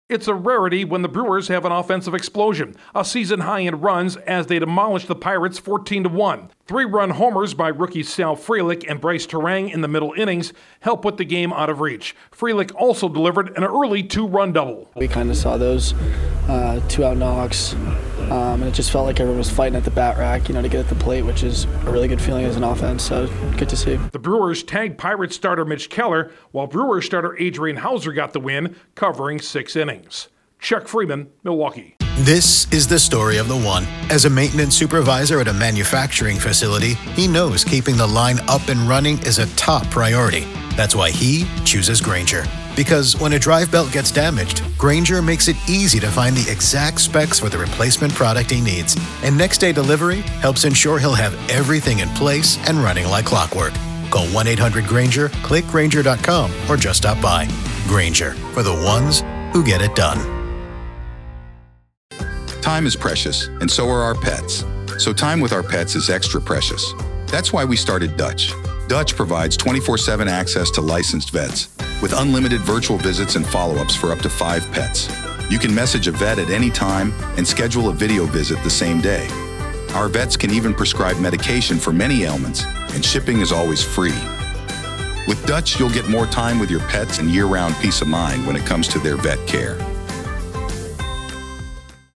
The Brewers blow past the Pirates. Correspondent